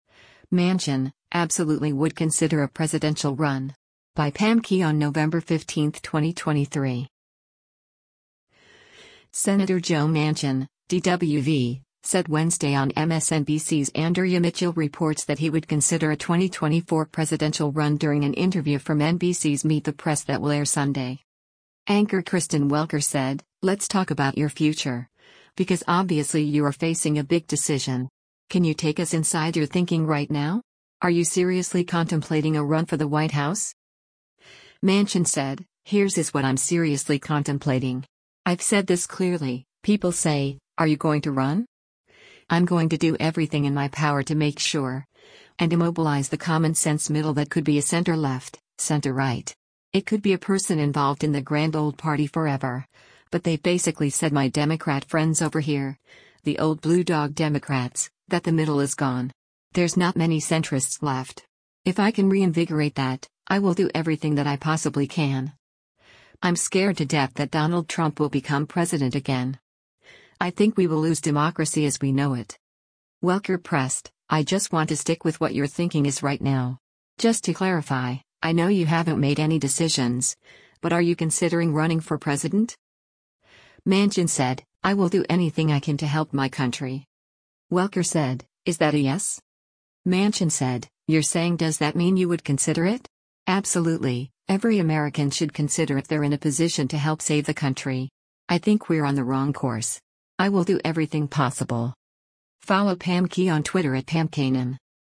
Senator Joe Manchin (D-WV) said Wednesday on MSNBC’s “Andrea Mitchell Reports” that he would consider a 2024 presidential run during an interview from NBC’s “Meet the Press” that will air Sunday.